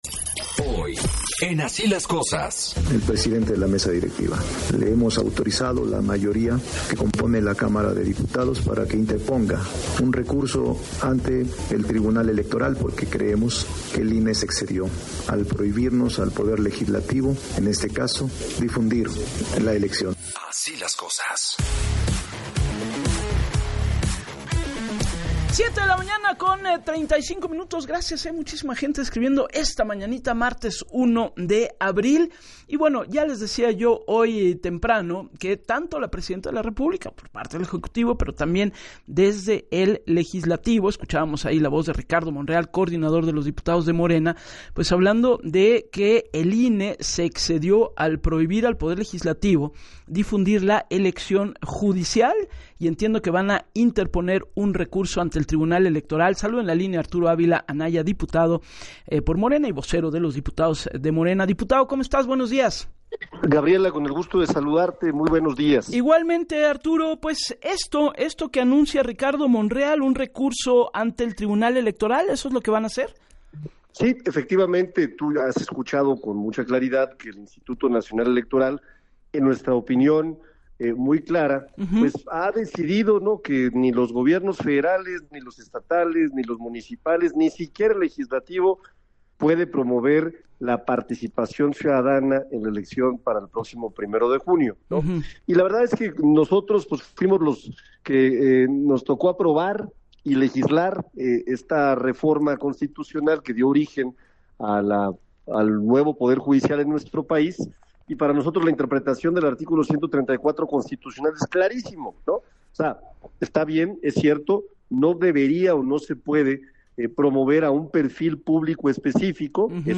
El vocero de los diputados morenistas, puntualizó en entrevista para “Así las Cosas” con Gabriela Warkentin, que “De acuerdo con el artículo 134 constitucional no se puede promover a un perfil público específico, pero si se puede promover el proceso de elección, incluso es deseable promover el evento público”.